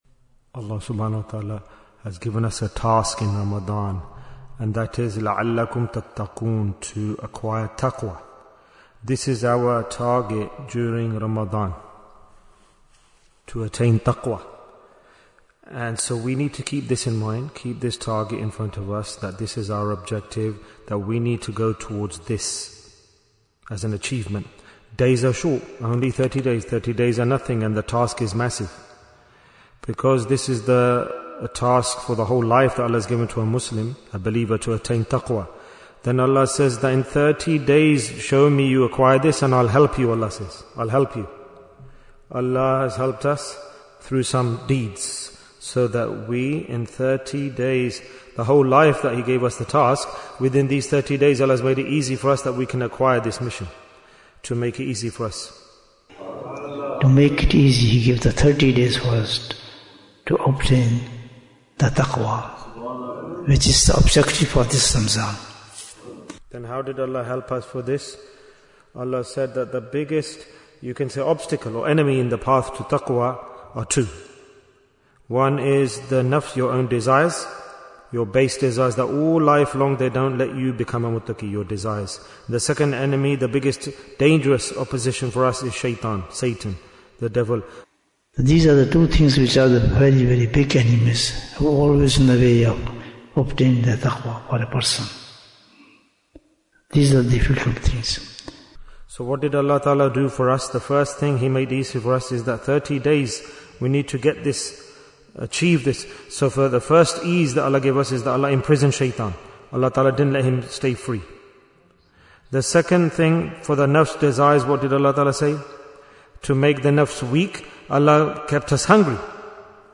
Jewels of Ramadhan 2026 - Episode 13 Bayan, 16 minutes24th February, 2026